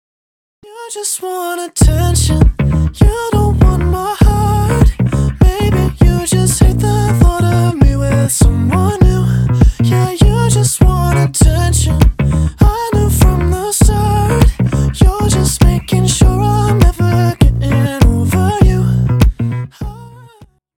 deep house
Cover